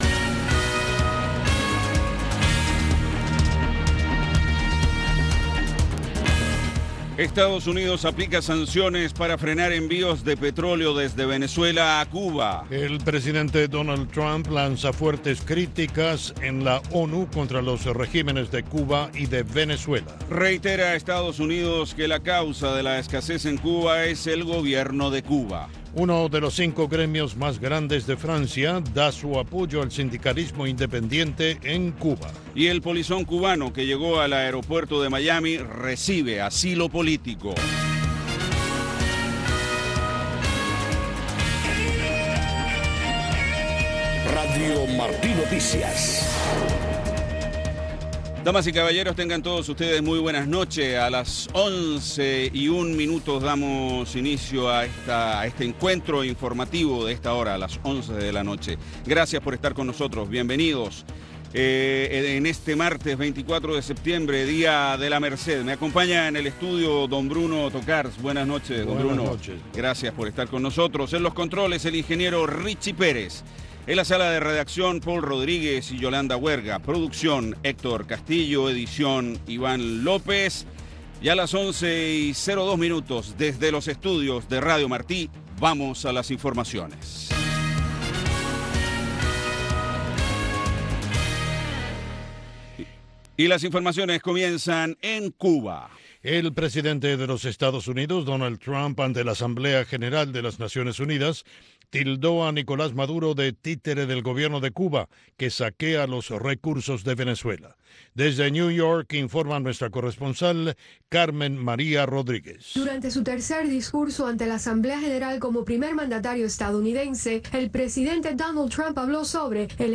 Noticiero de Radio Martí 11:00 PM